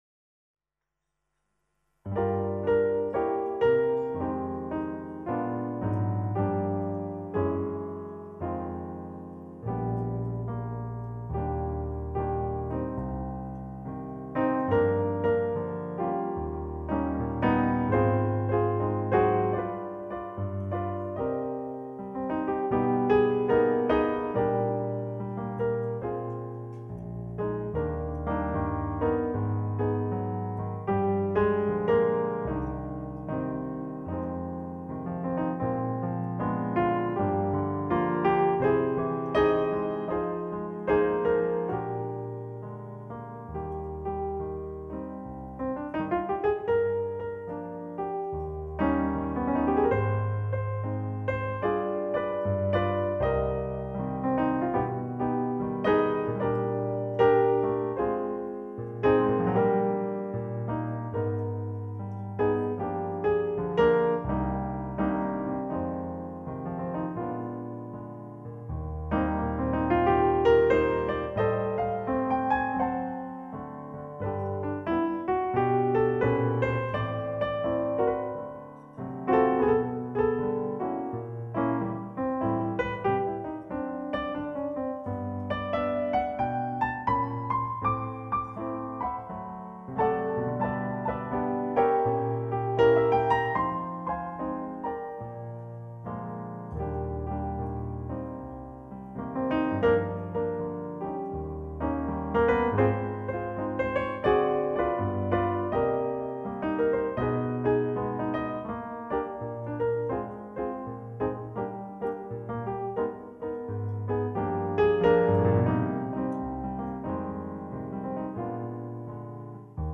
Classic Casablanca-style jazz.
solo piano